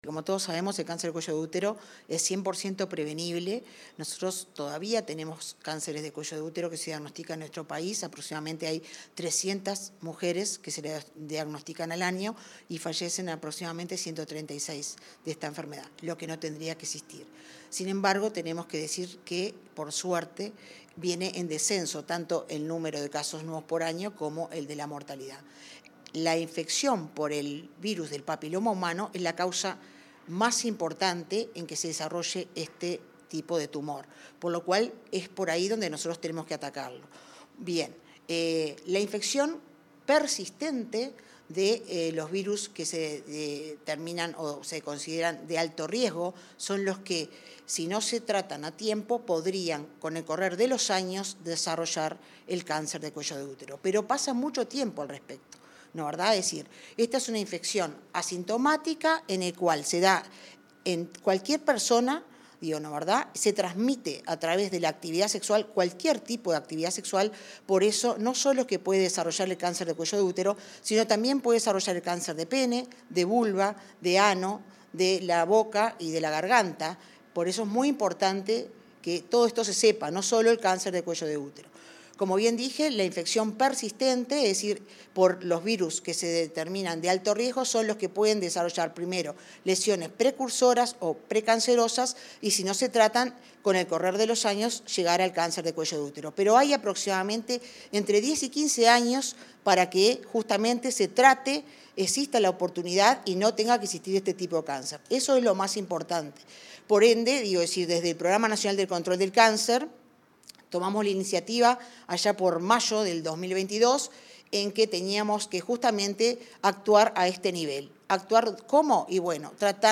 Entrevista a la directora del Pronaccan, Marisa Fazzino